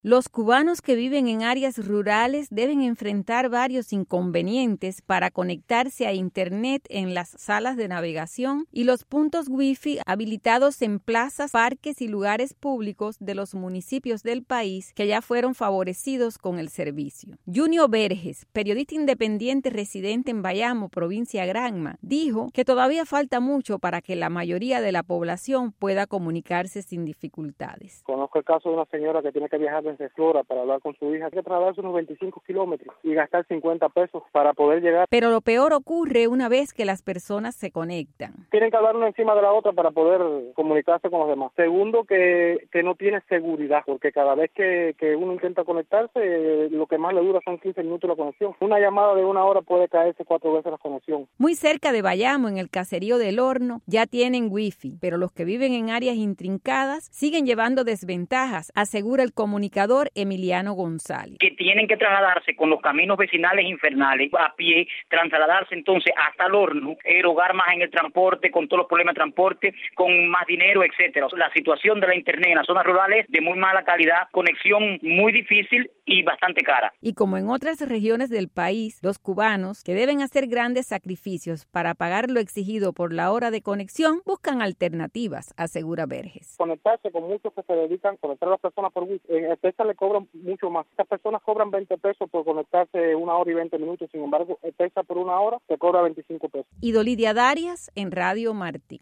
recogió opiniones de dos periodistas independientes y nos trae este informe.